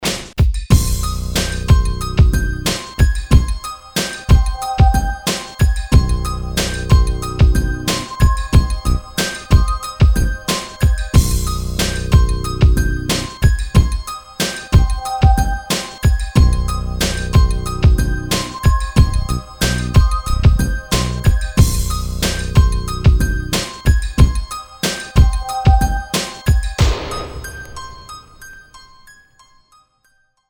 full mix